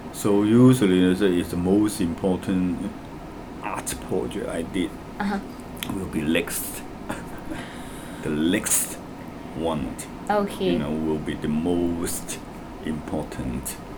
S1 = Hong Kong male S2 = Malaysian female Context: S2 has just asked S1 about the most exciting art project he has been involved in.
Intended Words : next Heard as : lext Discussion : S2 could not initially understand this word, because it starts with [l] rather than [n], as is common in Hong Kong English (Deterding, Wong & Kirkpatrick, 2006).